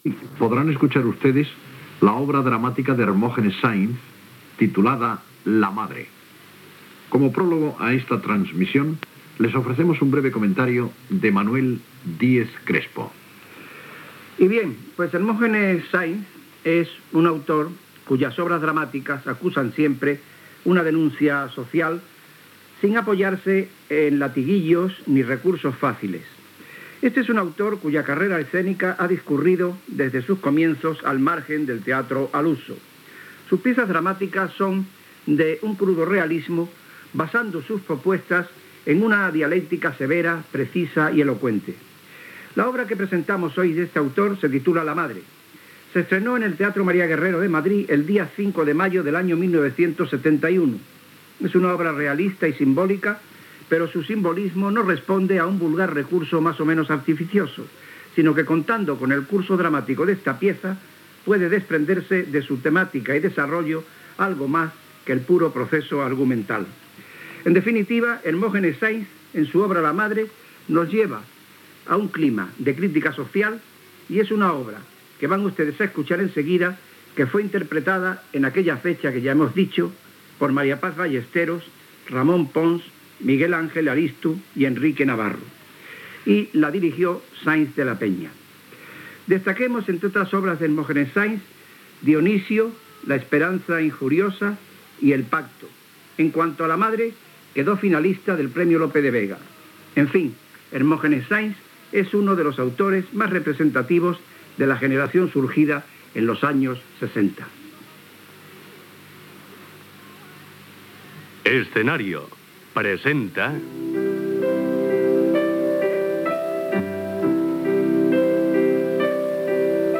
Presentació, comentari, careta del programa amb la versió radiofònica de l'obra "La madre" d'Hermógenes Sainz.
Ficció